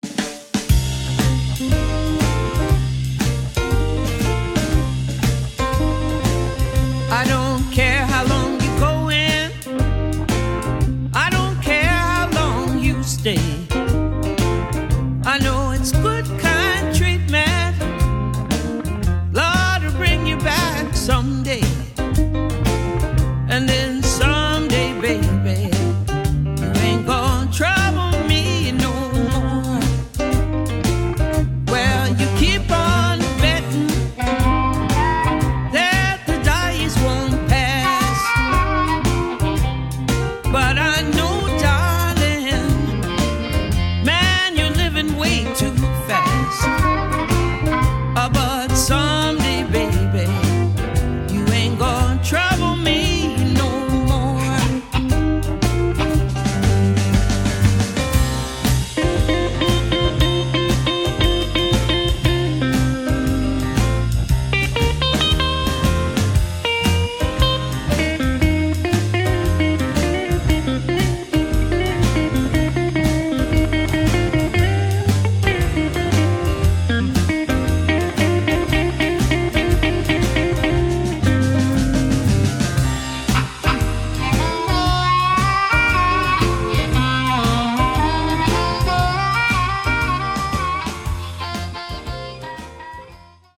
CONCERT HIGHLIGHTS!!